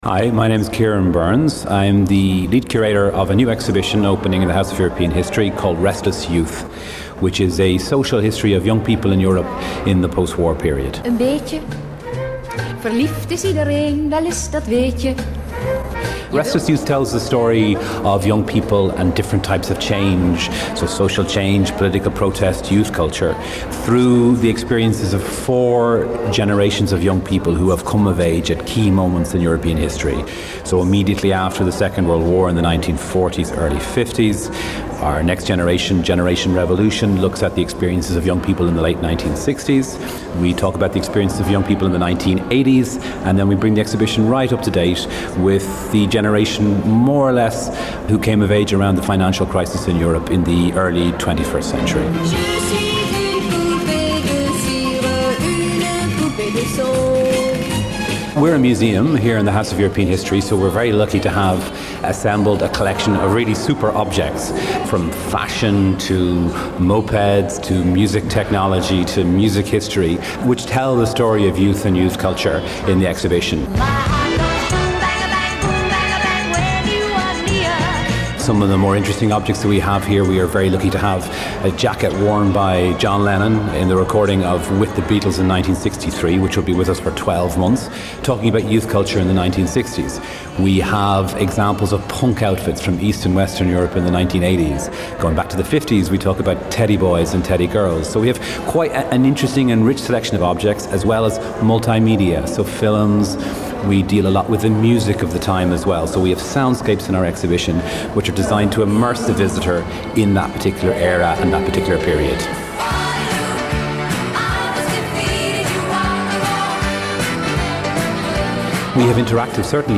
the lead curator of the show gives us a rundown.